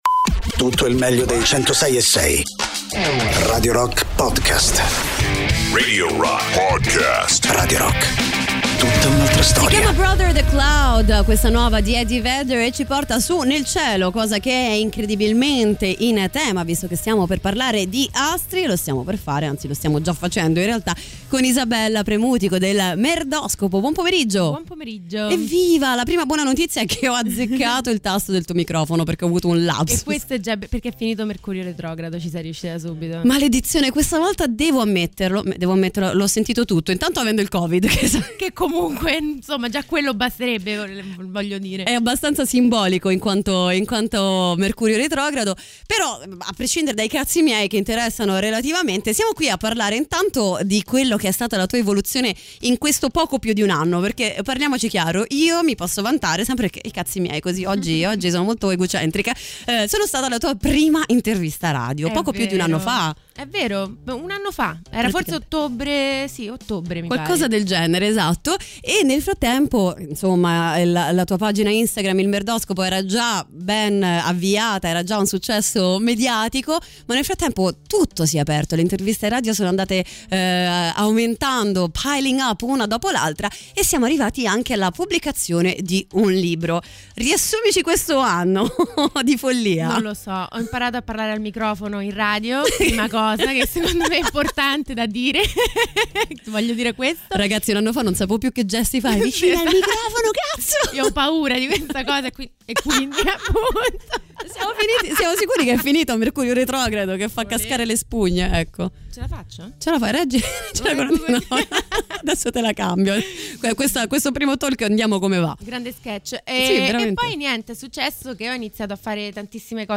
Intervista: Il Merdoscopo (05-02-22)